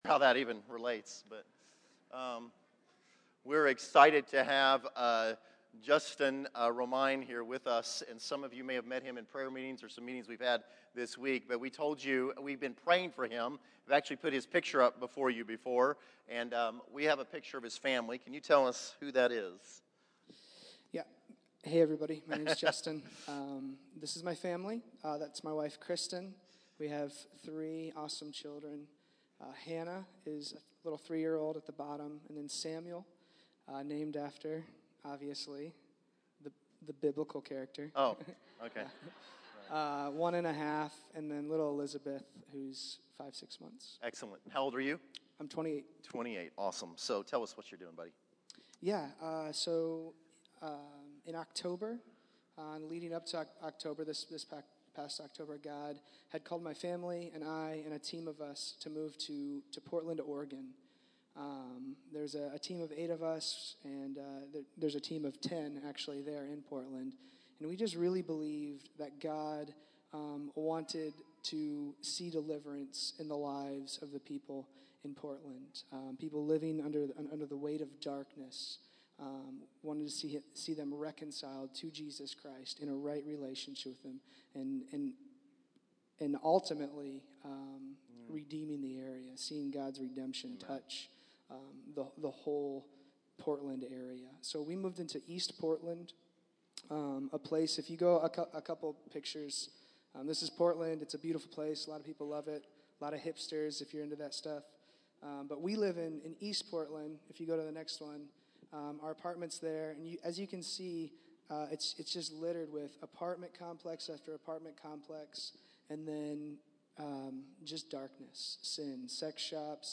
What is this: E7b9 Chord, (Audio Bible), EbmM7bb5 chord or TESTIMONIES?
TESTIMONIES